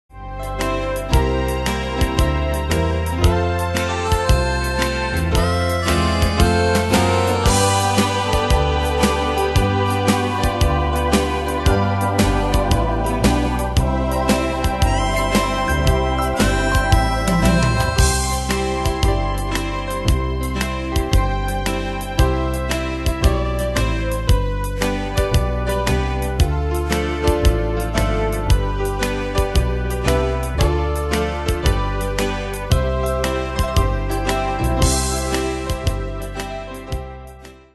Style: Country Ane/Year: 1985 Tempo: 114 Durée/Time: 3.04
Danse/Dance: TwoSteps Cat Id.
Pro Backing Tracks